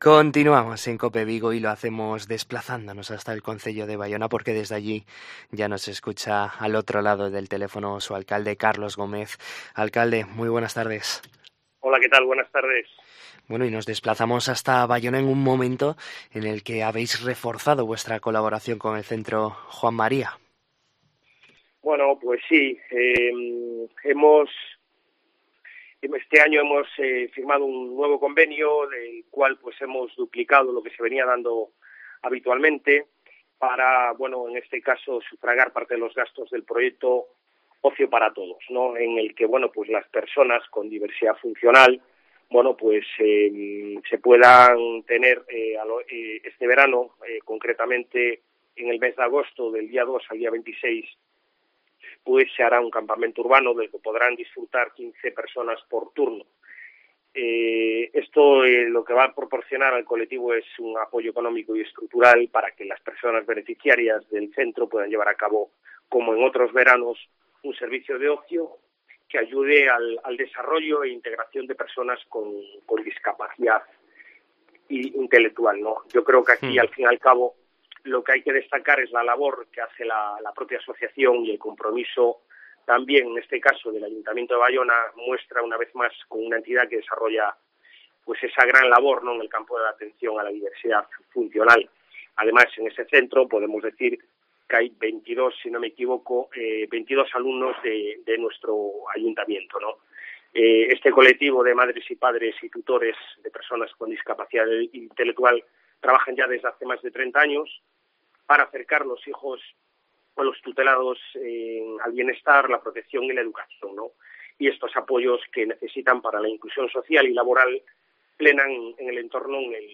En COPE Vigo hablamos con el alcalde de Baiona, Carlos Gómez, para conocer la actualidad de esta localidad del sur de la provincia de Pontevedra